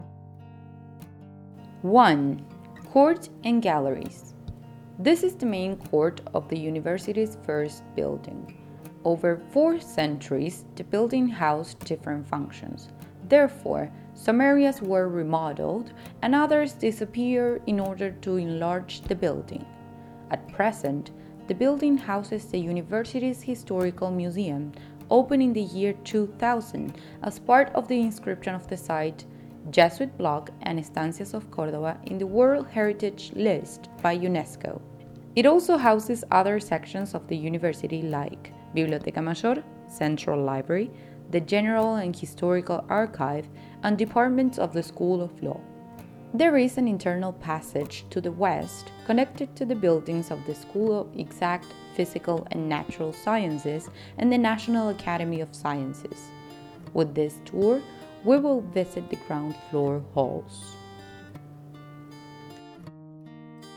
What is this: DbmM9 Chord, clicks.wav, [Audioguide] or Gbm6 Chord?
[Audioguide]